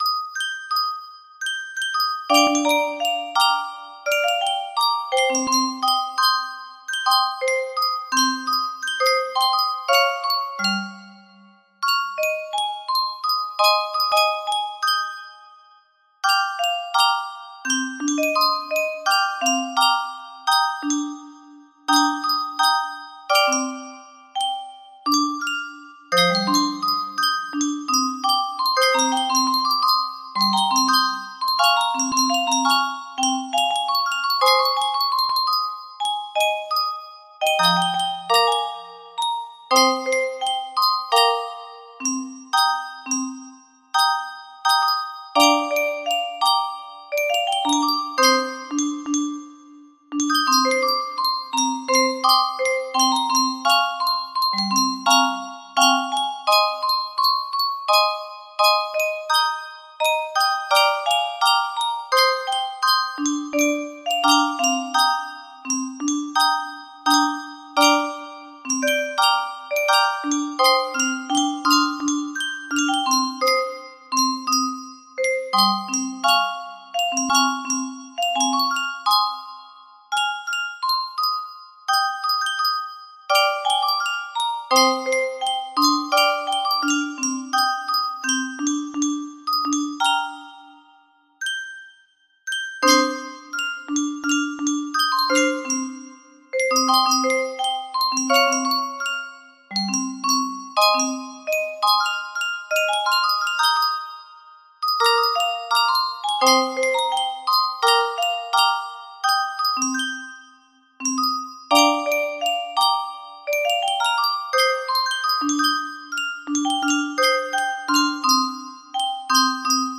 Grand Illusions 30 (F scale)
An arranged version